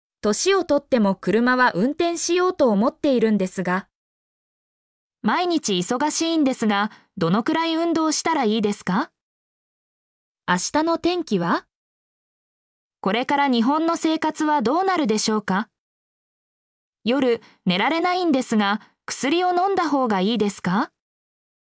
mp3-output-ttsfreedotcom-53_HyxkNV38.mp3